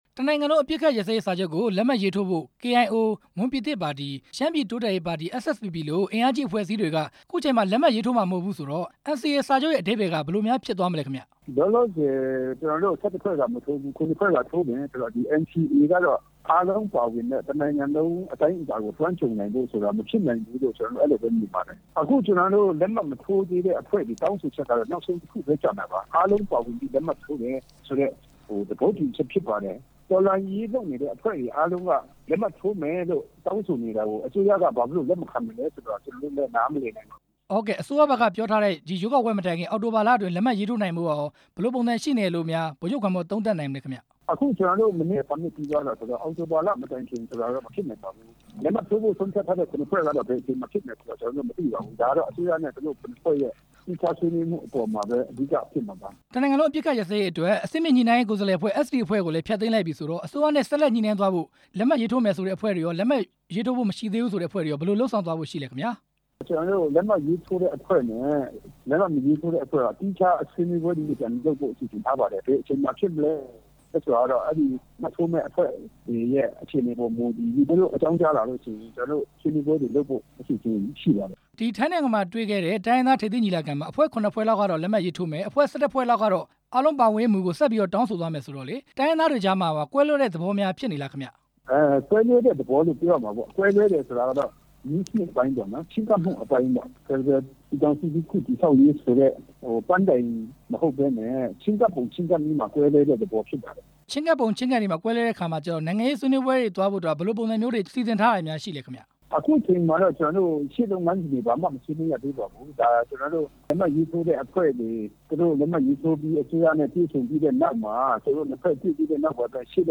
NCA လက်မှတ်ရေးထိုးရေး ဗိုလ်ချုပ်ဂွမ်မော်နဲ့ မေးမြန်းချက်